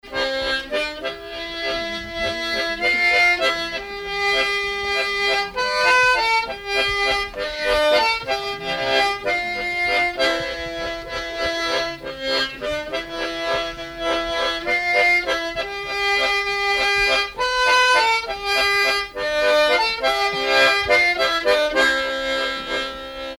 Valse
danse : valse
circonstance : bal, dancerie
Pièce musicale inédite